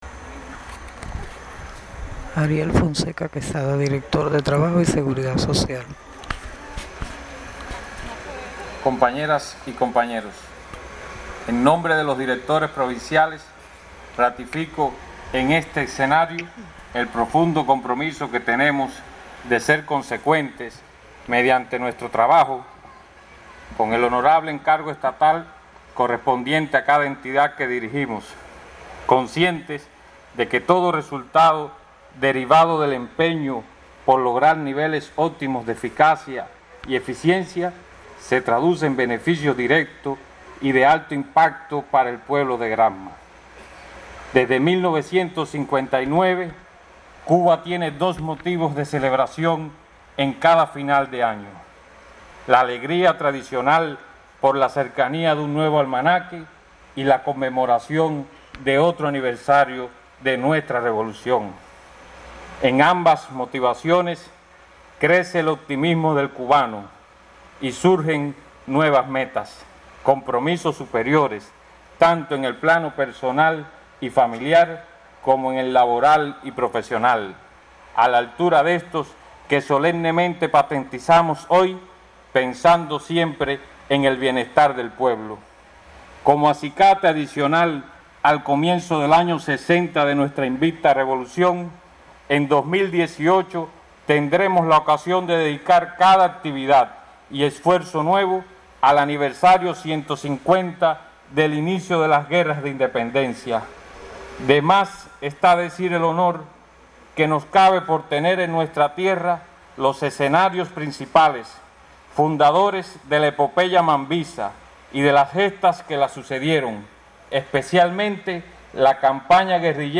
La significativa jornada, efectuada la noche del miércoles en el parque museo Ñico López, de Bayamo, se hizo como saludo al aniversario 150 del inicio de las luchas independentistas en Cuba, y marcó pauta para la continuidad en 2018 de una labor encaminada a mantener ante cualquier circunstancia las conquistas de la Revolución.
Ariel Fonseca Quesada, director provincial de Trabajo y Seguridad Social, dijo en nombre de todos los directores: Palabras de Ariel Fonseca Quesada, director provincial de Trabajo y Seguridad Social.